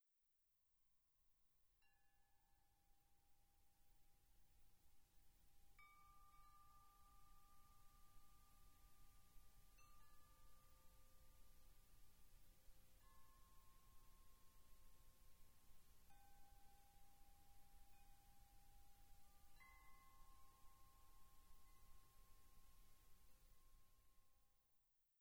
ModChimes produce beautiful, distinct, bell-like tones
D4 E4 G4 A4 C5
D4-E4-G4-A4-C5.mp3